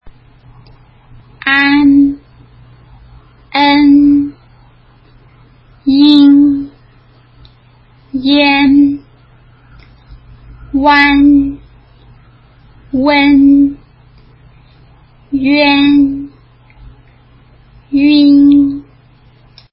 【母音＋n】舌先を上の歯の裏につけて、｢ヌ｣と｢ン｣の中間の音を短く発音するイメージ。
an ｢ア｣のあと、｢ヌ｣と｢ン｣の中間音を短く発音するイメージ。
en ｢エ｣のあと、｢ヌ｣と｢ン｣の中間音を短く発音するイメージ。
ian ｢イエ｣のあと、｢ヌ｣と｢ン｣の中間音を短く発音するイメージ。
uan 唇を丸く突き出した｢ウア｣のあと、｢ヌ｣と｢ン｣の中間音を短く発音するイメージ。